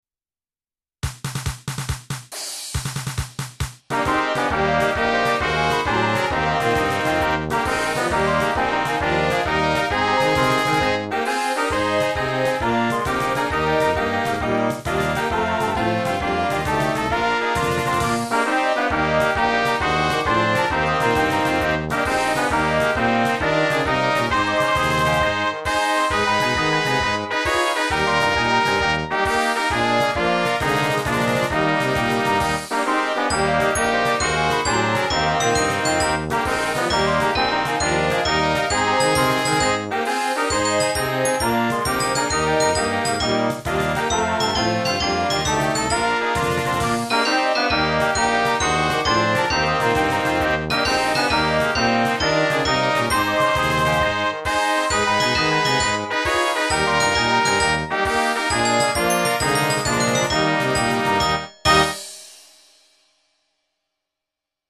high school fight song